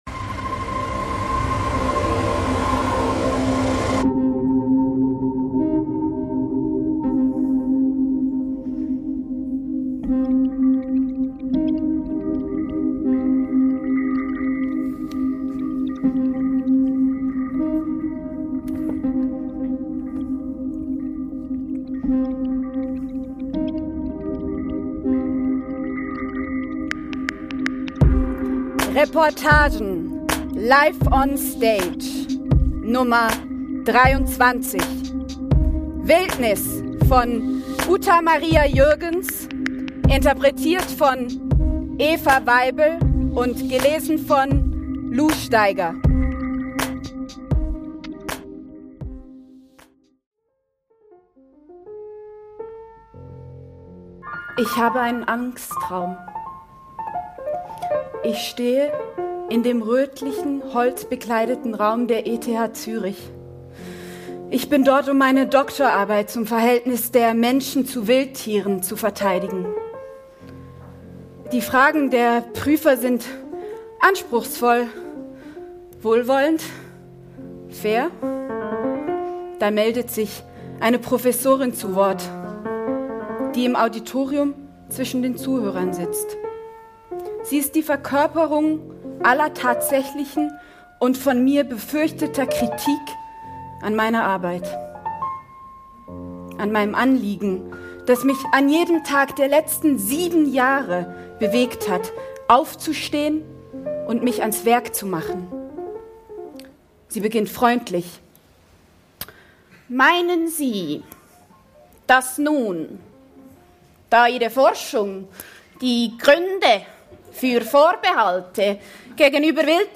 Im anschliessenden Expertengespräch